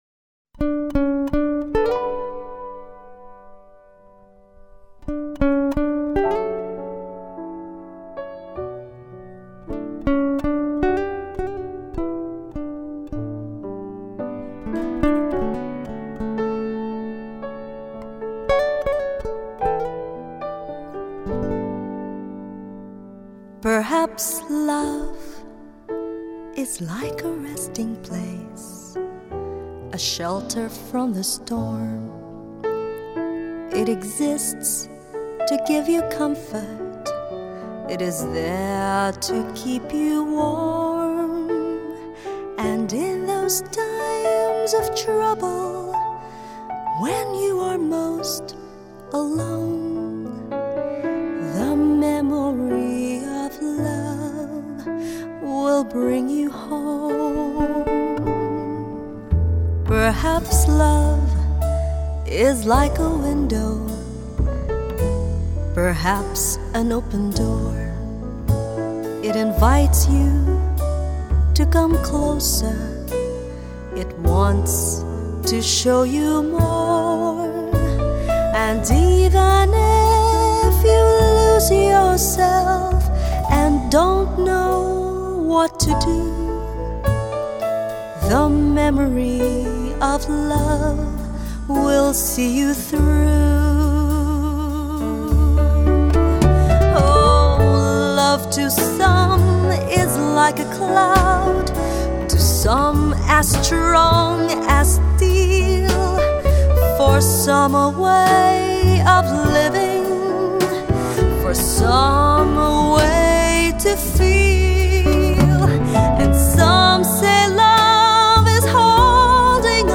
Thiệt nhẹ nhàng